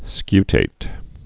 (skytāt)